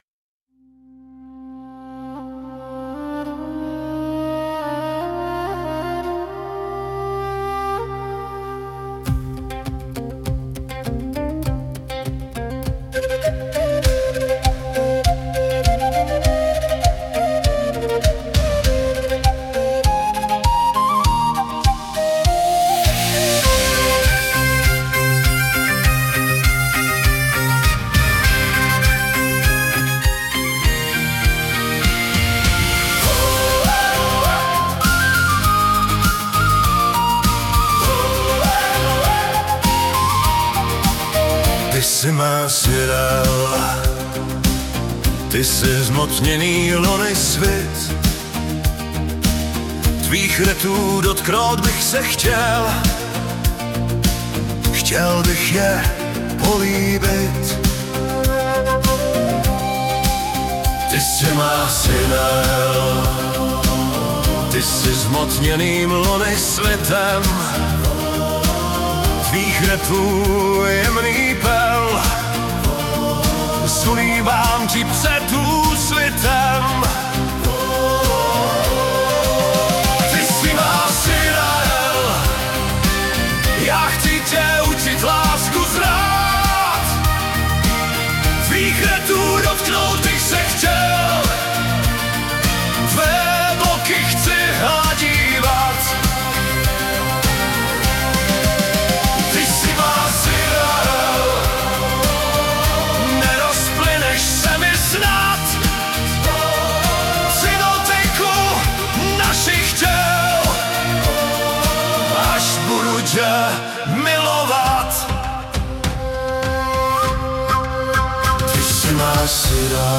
hudba, zpěv: AI
Škoda, že hlas je AI...prostě dokonalost a kořen mandragóry vytrzený ve smrtelné křeči pod šibenicí... není :)